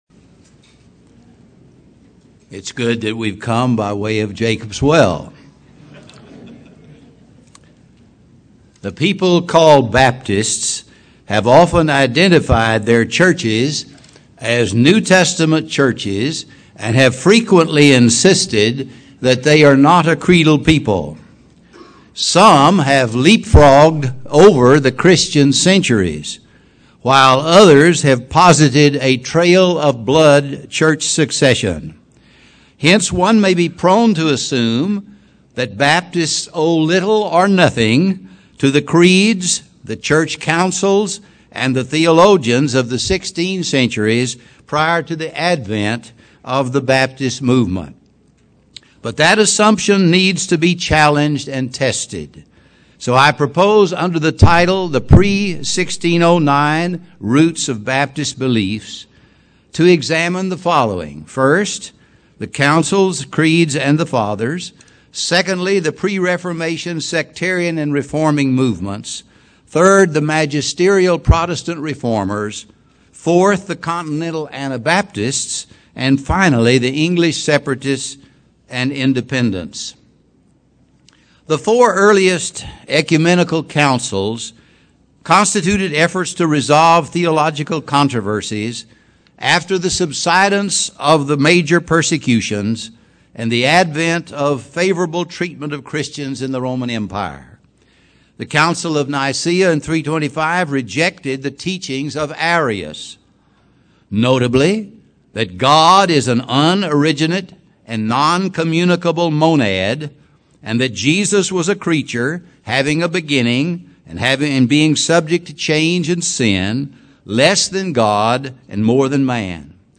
Baptist Identity Conference